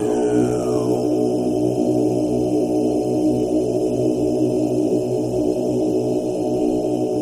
Monk Voices Low Chant